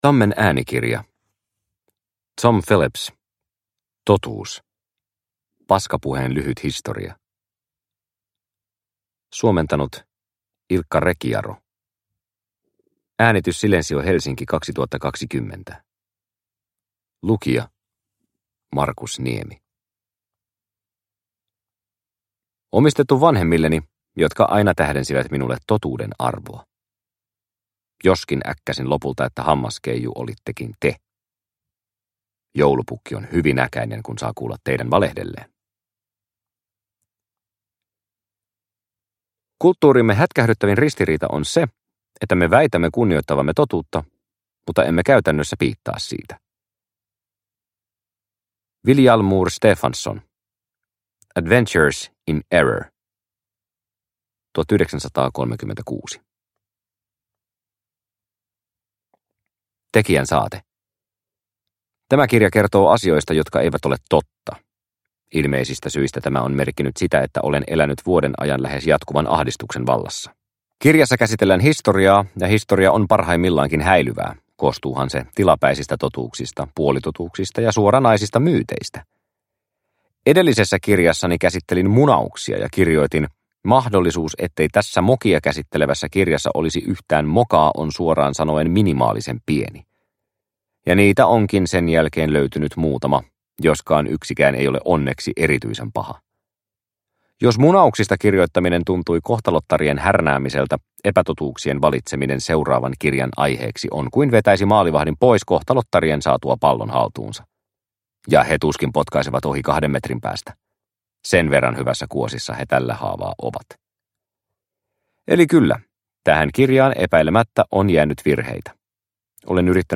Totuus - Paskapuheen lyhyt historia – Ljudbok – Laddas ner